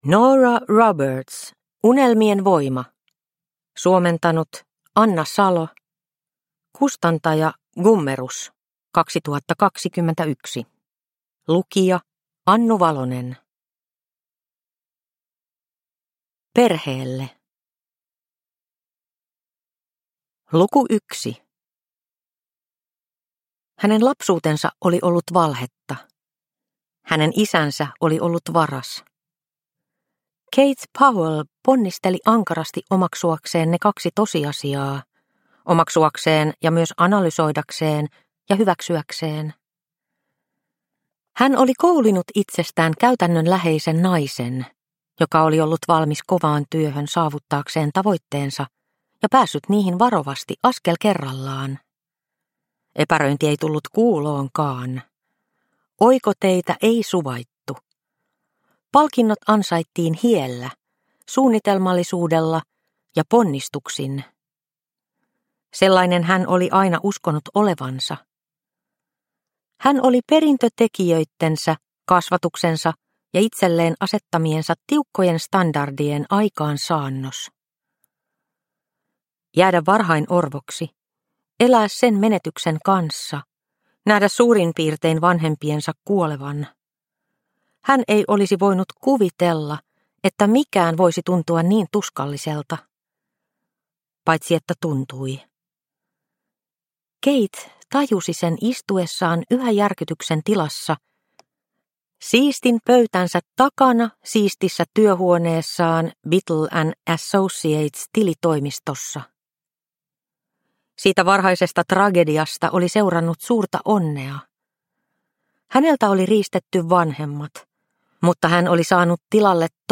Unelmien voima – Ljudbok – Laddas ner